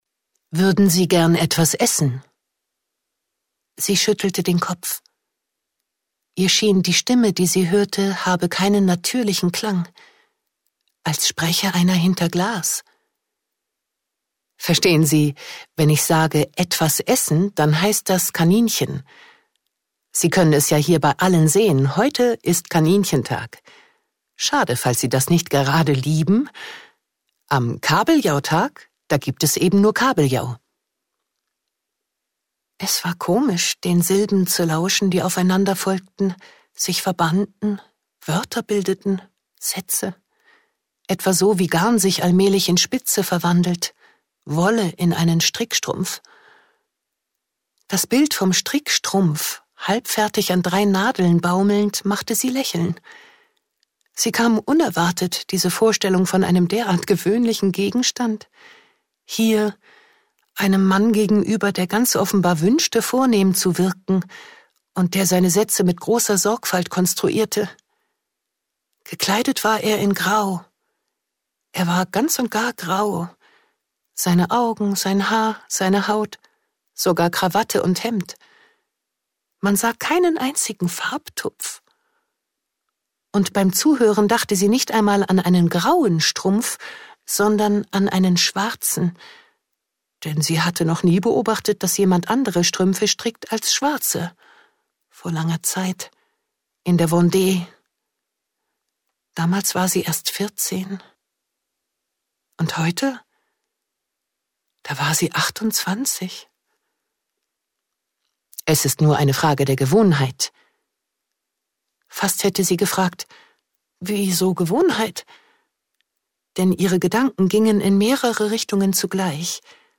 Ungekürzte Lesung mit Wiebke Puls. Eine vornehm gekleidete Frau sitzt betrunken in einem Pariser Restaurant.